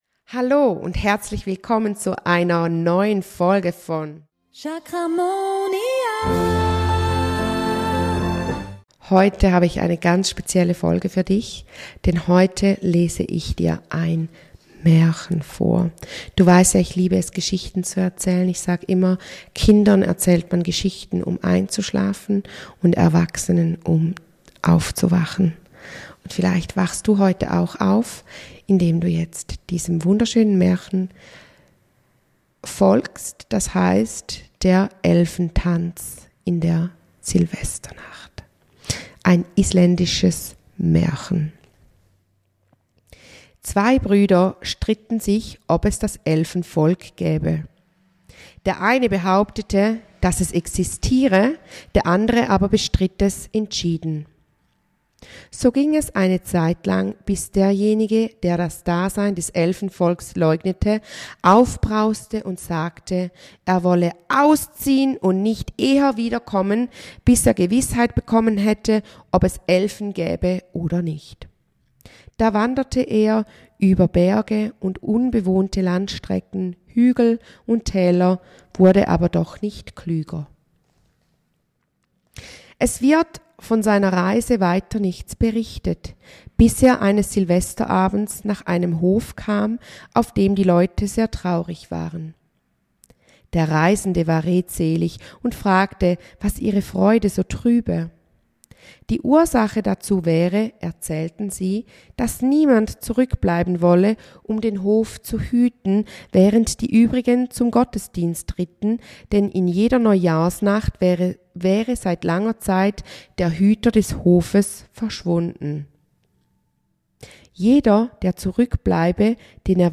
#124: Märchenstunde: der Elfentanz in der Silvesternacht | Chakramonia